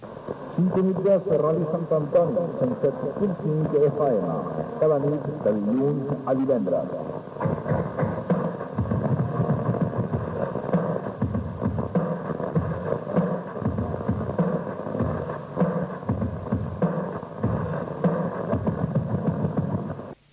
Identificació